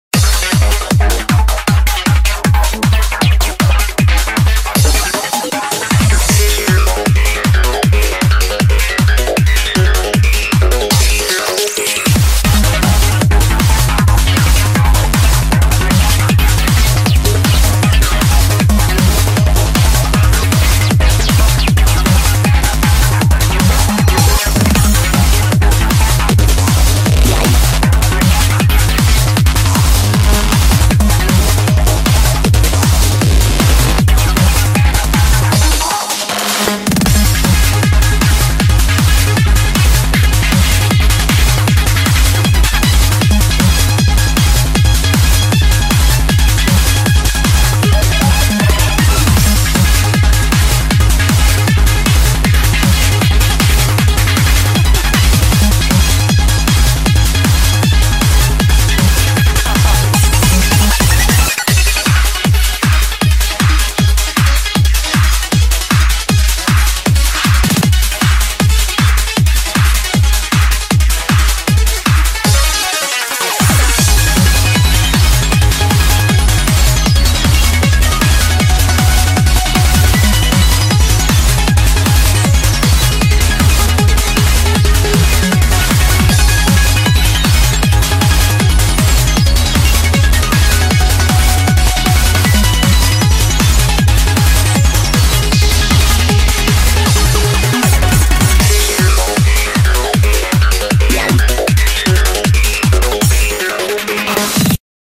BPM156
Audio QualityPerfect (Low Quality)